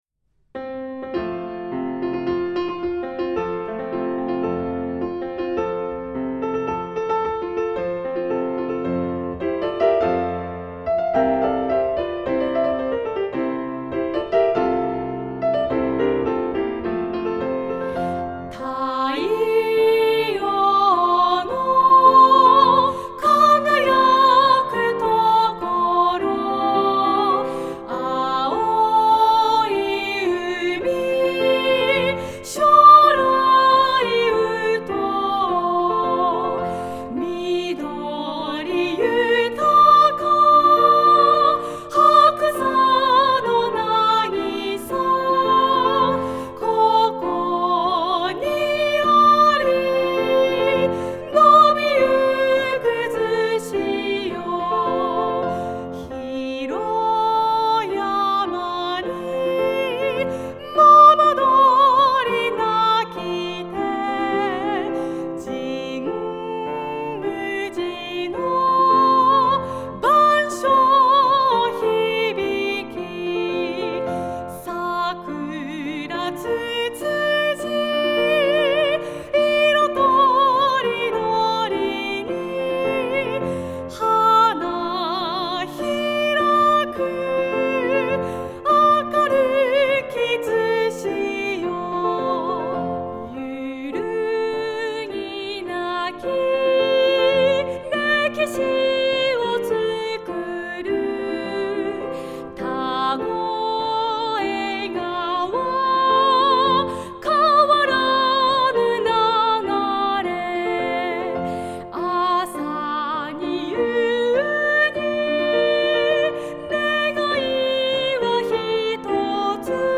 お二人のすてきなハーモニーをぜひお聴きください。